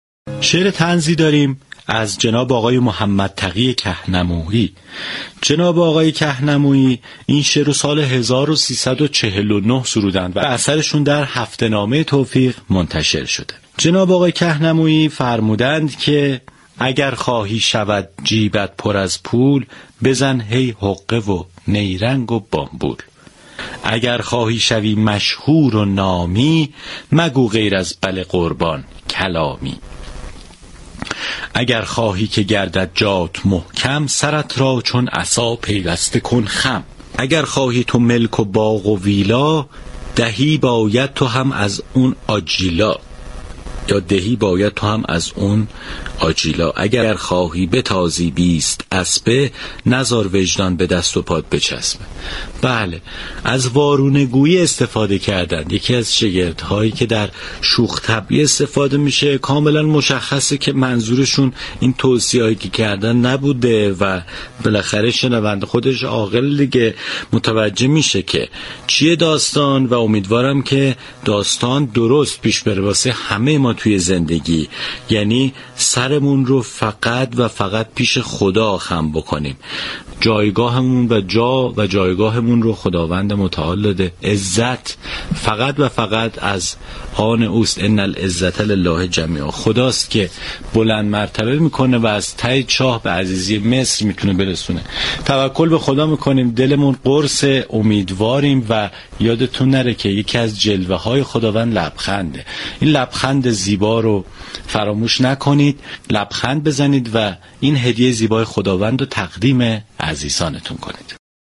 شعر طنز